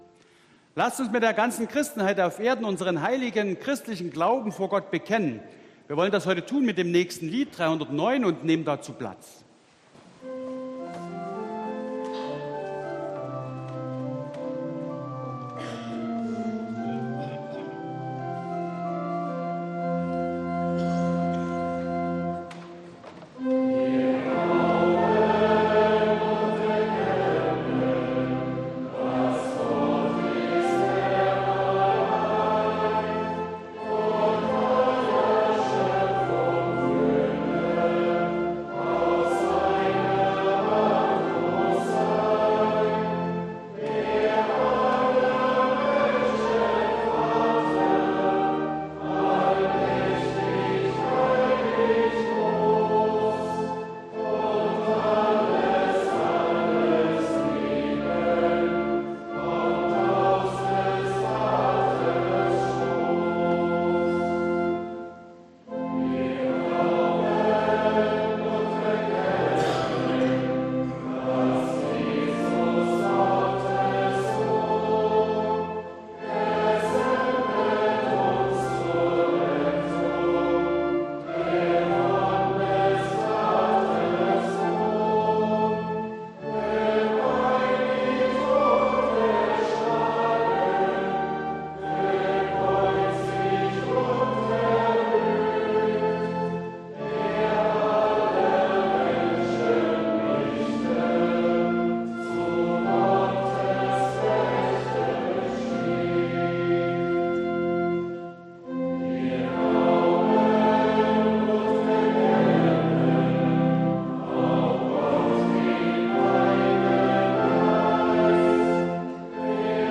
Audiomitschnitt unseres Gottesdienstes vom 3.Advent 2022.